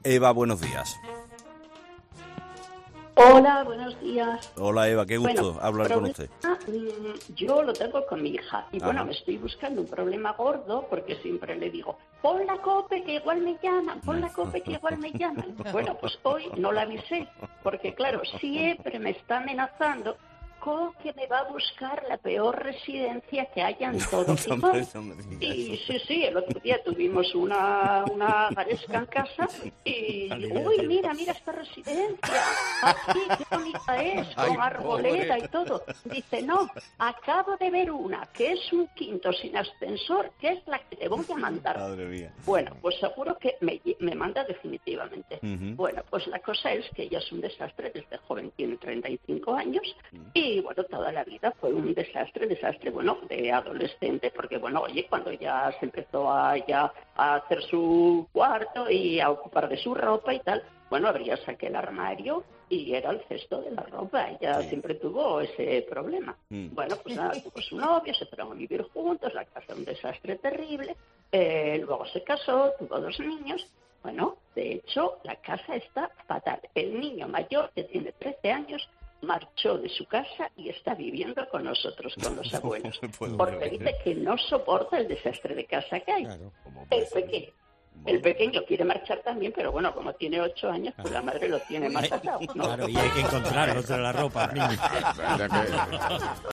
Pues bien, una oyente de Carlos Herrera tiene la respuesta y un ejemplo de ello.